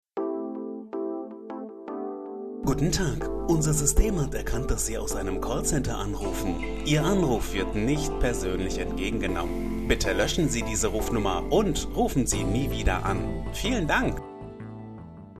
Hier unten findest du zwei Bandansagen zum kostenlosen runterladen.
Ansage_HP_Unknown_Number.mp3